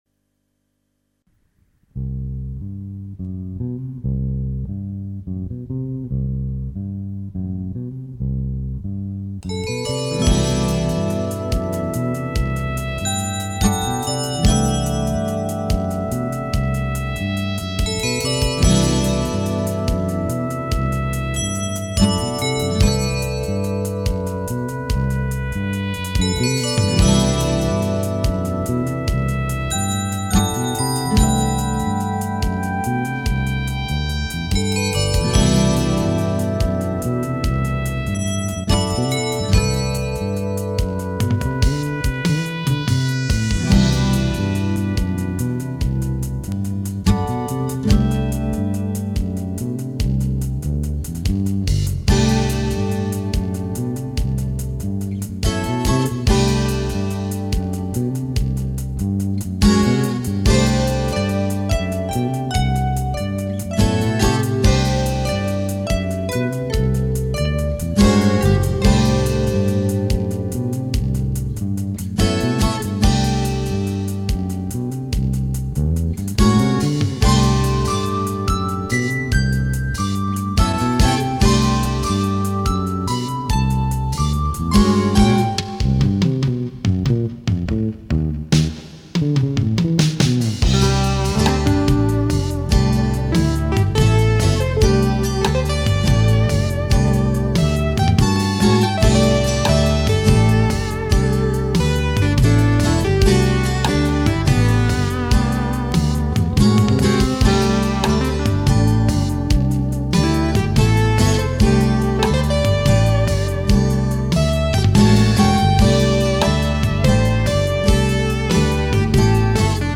Also jammten wir mit Gitarre und Bass fünf Minuten lang zu einem einfachen Grundbeat.
Acoustic Guitar
Electric Guitar Solo
Choir
Bass, Drum Programming, Keys&Choir: